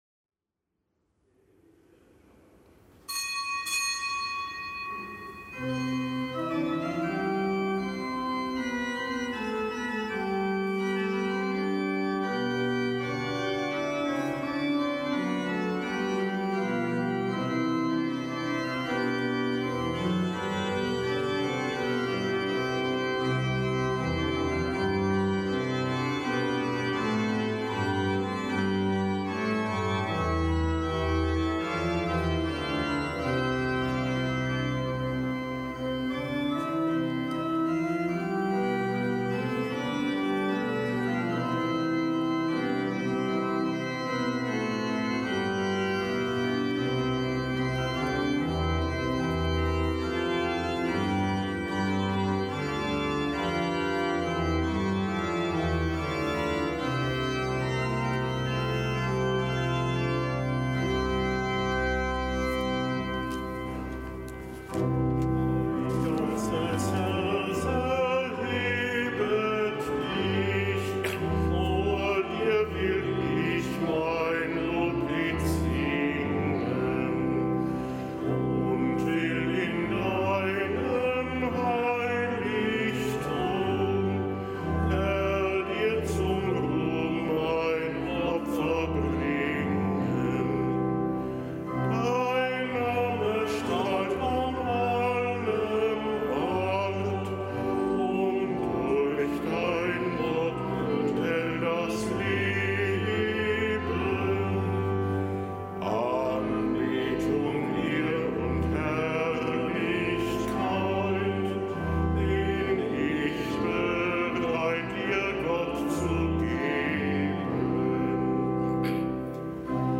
Kapitelsmesse am Donnerstag der einunddreißigsten Woche im Jahreskreis